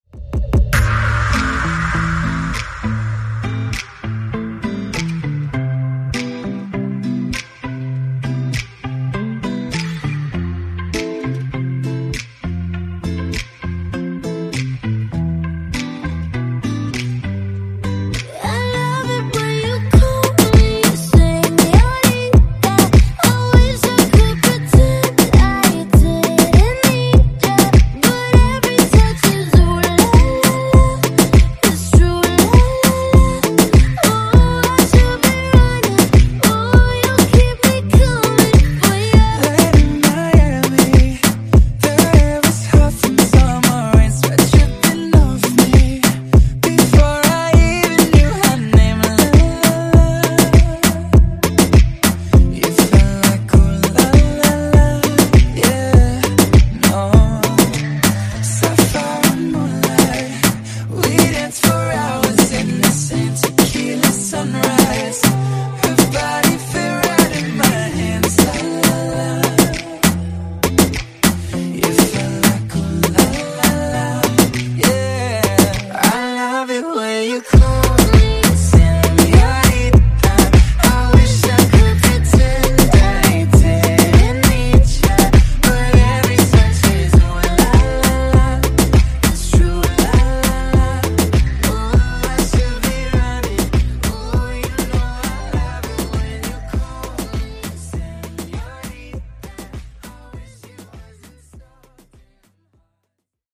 Genre: RE-DRUM Version: Clean BPM: 100 Time